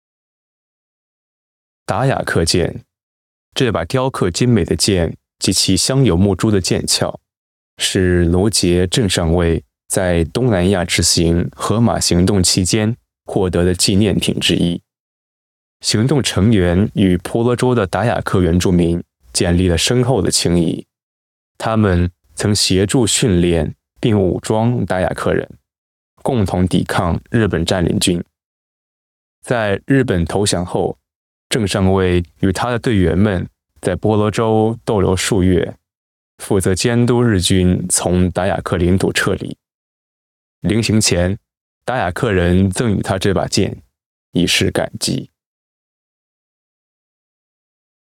Special Operations Voiceovers